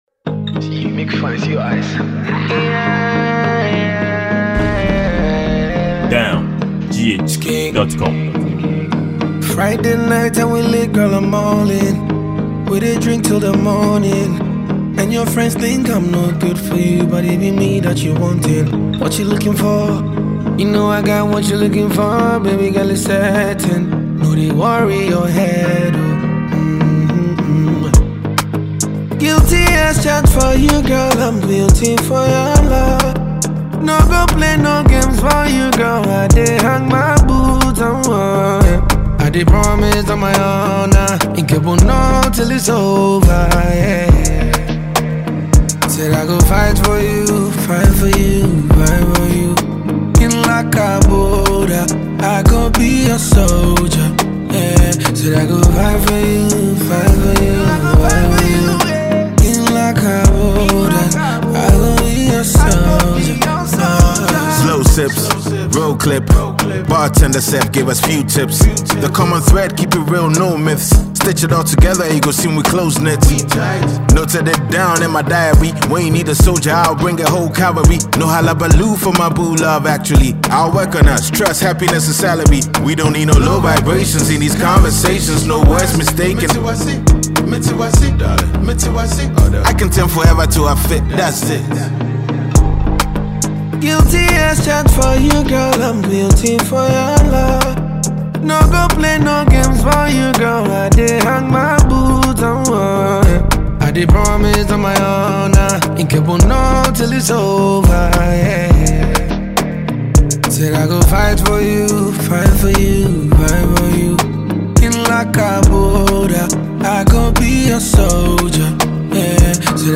Ghanaian top-notch rapper
afrobeat highlife singer